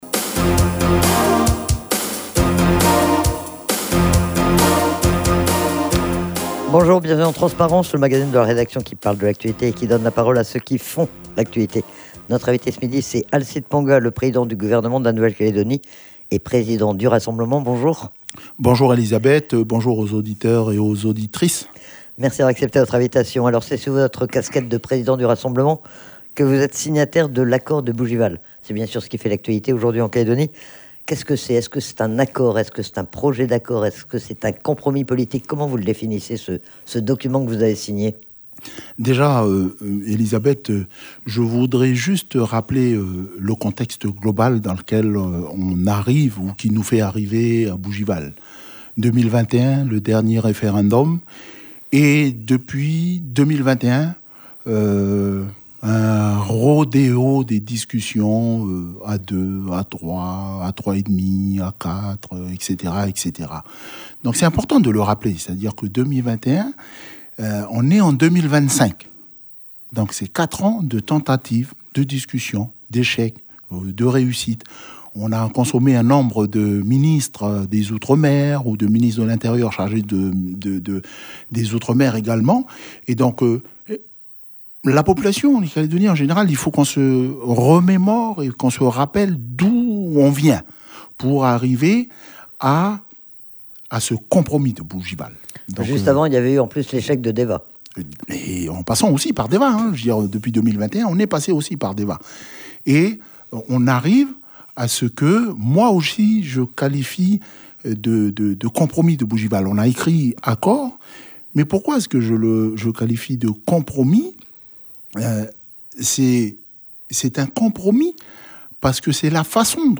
C'est Alcide Ponga, le président du gouvernement de la Nouvelle-Calédonie et président du Rassemblement qui était, ce midi, l'invité du magazine Transparence. Il a été interrogé sur les négociations de Bougival, auxquelles il a participé, et sur les réactions que suscite le projet d'accord. Il a également été interrogé sur les réformes mises en œuvre par le gouvernement et sur la politique régionale de la Calédonie.